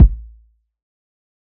TC Kick 14.wav